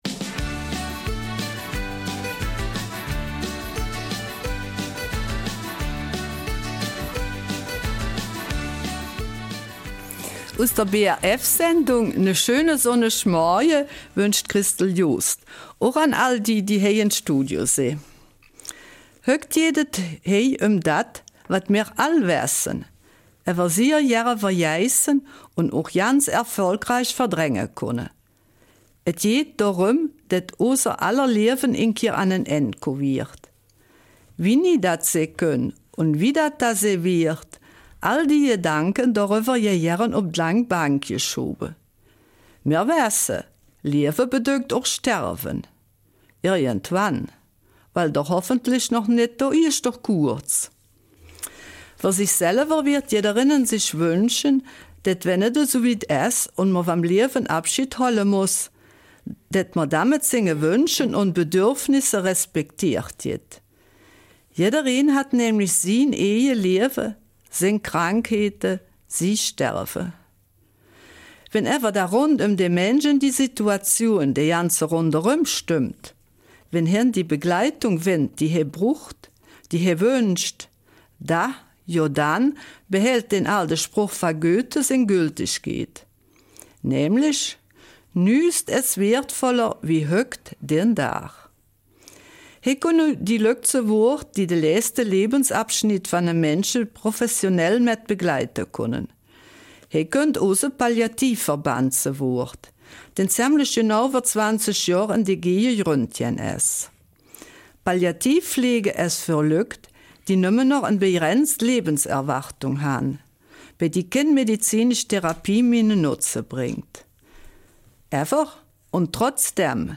Eifeler Mundart: 20 Jahre Palliativpflegeverband in der DG 17.